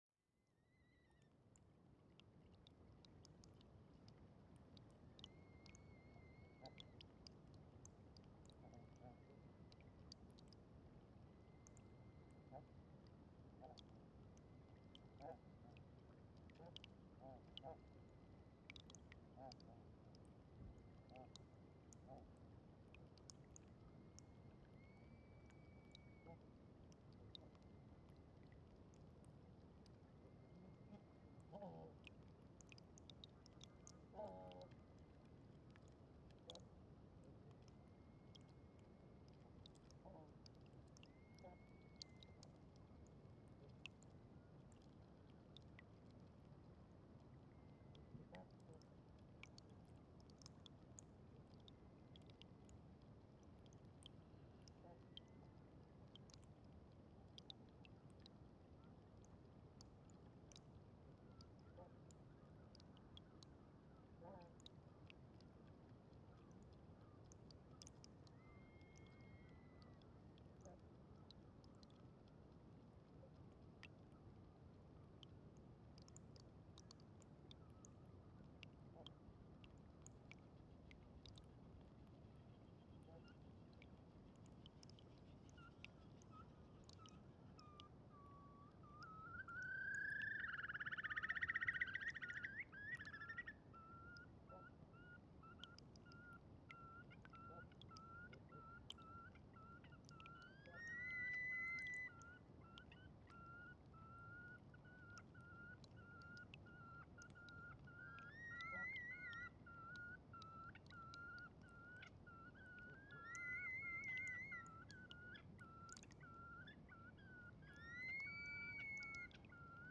Soundscape at Fjallsjökull glacier
This place is a small glacial corrie south of Fjallsjökull glacier.
Arctic flora brings lots of birds to this place so the soundscape can be very interesting with a rumbling glacier in the background.
The time is around 5 o’clock in the morning. Flock of noisy Barnacle geese has already landed not far away from the microphones. Through the whole recording these Barnacle geese come closer and closer to the microphones, so be careful, in the end they get loud. Early in the recording two Whimbrels sing an interestingly long „love song“. Other birds are audible too, like Golden plover, Dunlin, Rock Ptarmigan and Red Throated Diver. Once, about 2km away, a big piece of ice breaks off the glacier into the lagoon with noisy consequences. Background noise is mainly a surf from the ocean behind the microphones, about 5 km away.